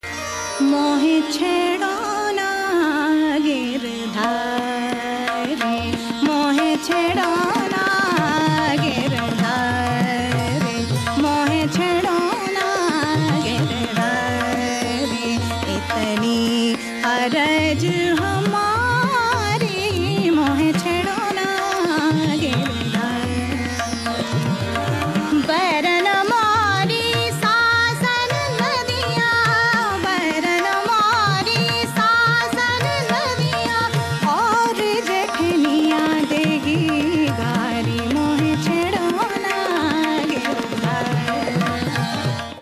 tabla
harmonium
12:26 statement of antarā
12:39 ākār / gamak tāns